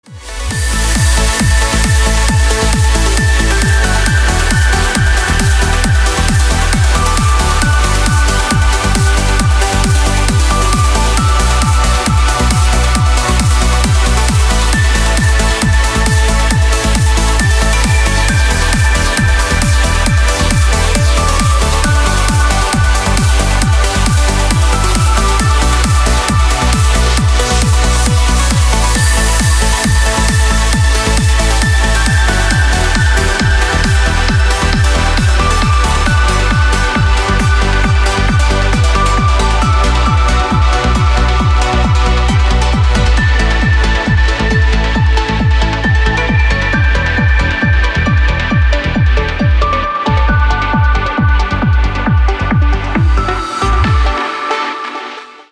красивые
dance
Electronic
спокойные
без слов
Trance